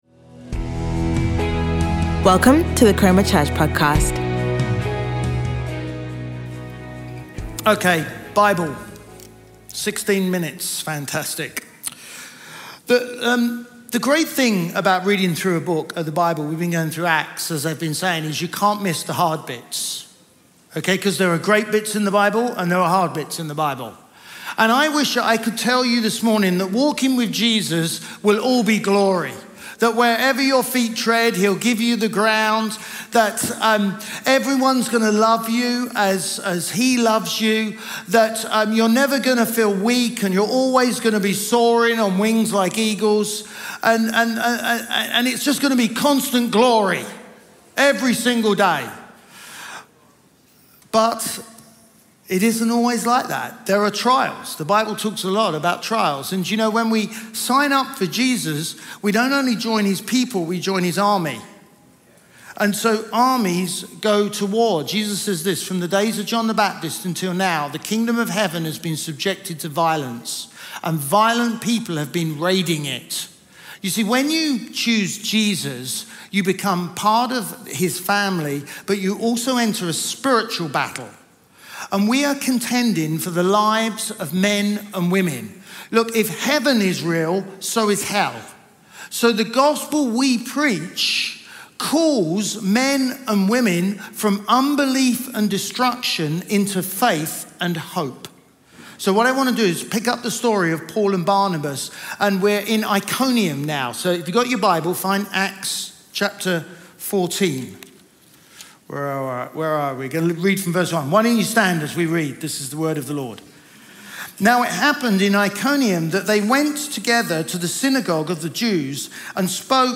Chroma Church Live Stream
Chroma Church - Sunday Sermon